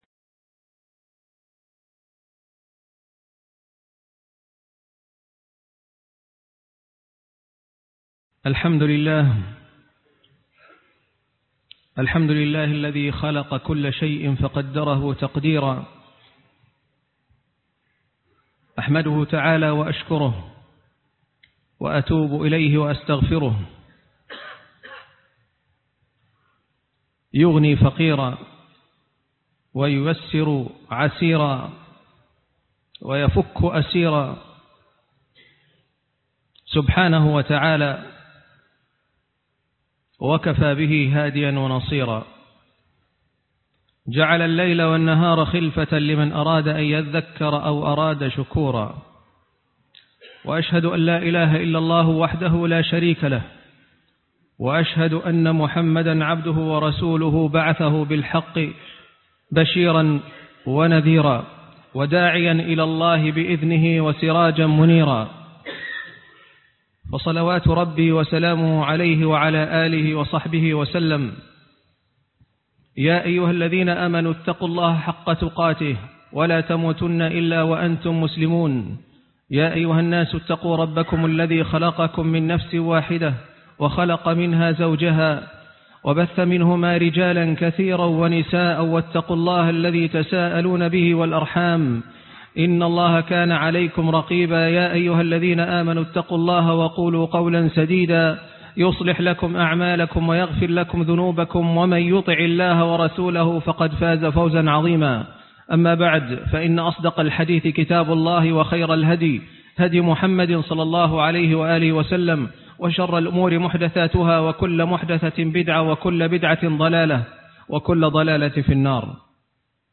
نهاية عام وبداية عام - خطبة الجمعة